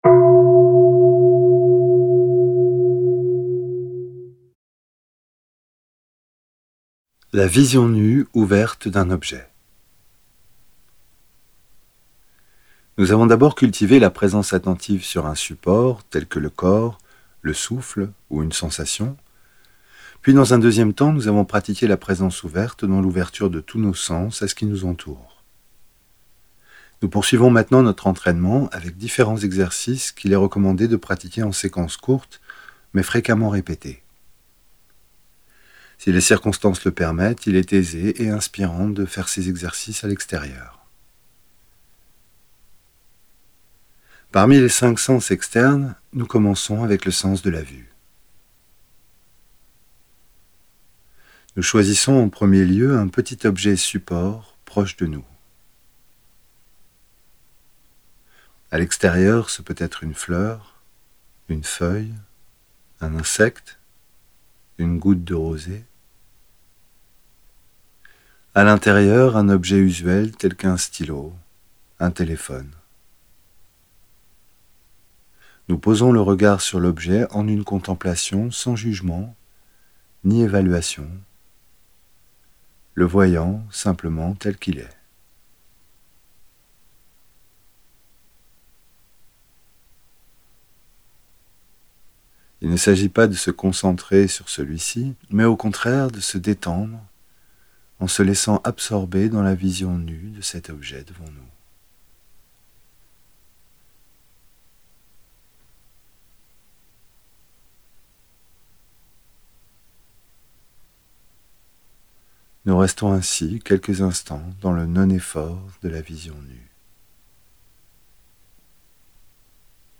Audio femme